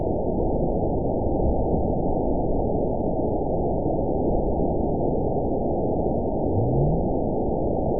event 920360 date 03/18/24 time 03:58:59 GMT (1 month, 1 week ago) score 9.47 location TSS-AB07 detected by nrw target species NRW annotations +NRW Spectrogram: Frequency (kHz) vs. Time (s) audio not available .wav